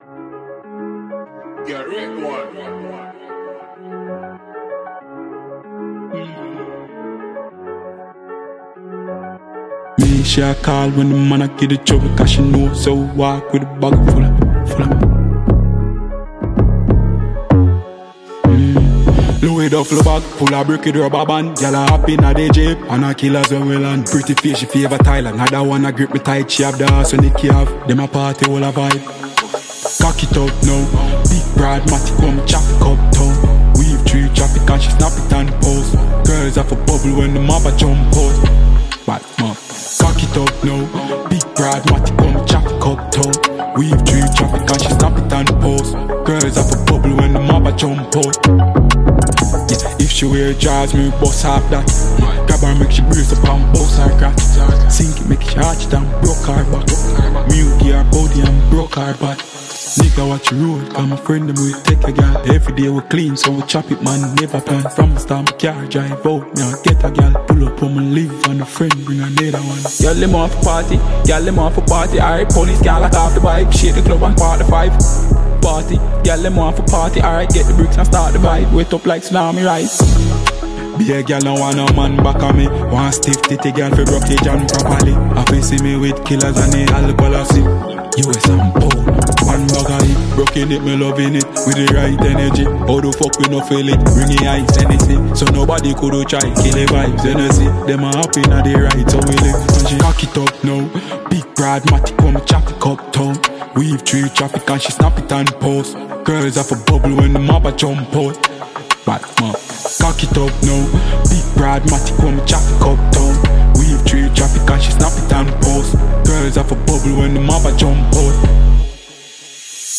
Dancehall
pure dancehall fire